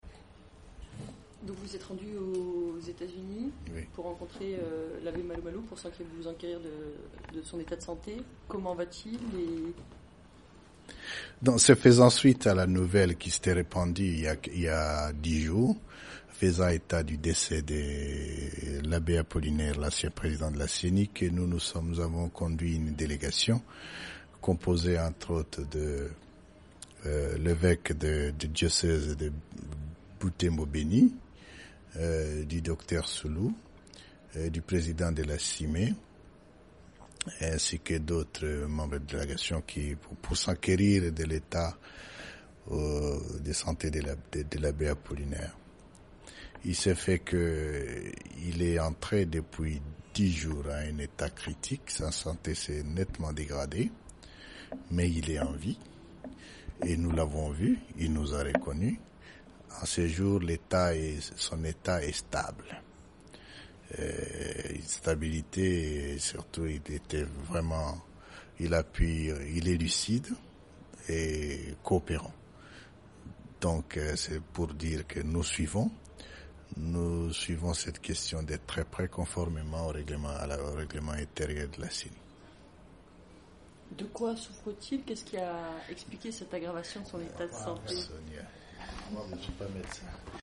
Corneille Nanga dément la mort de Malu Malu, au micro de Top Congo